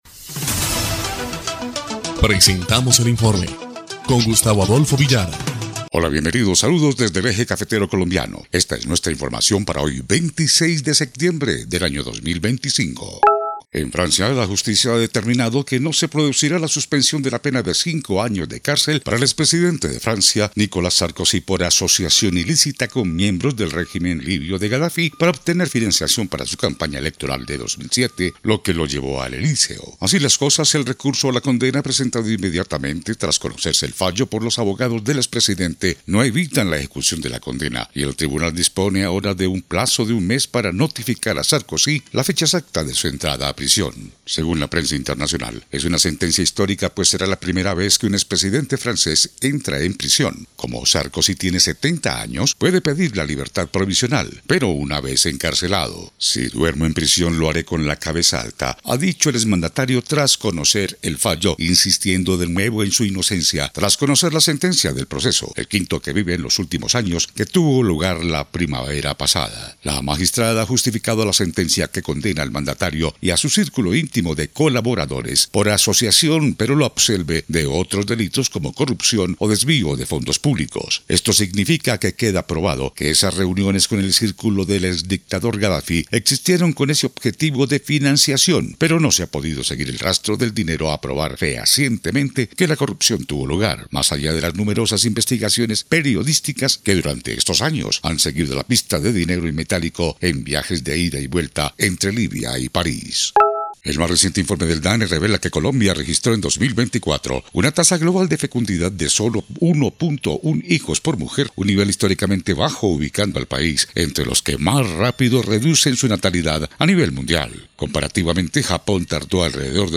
EL INFORME 1° Clip de Noticias del 26 de septiembre de 2025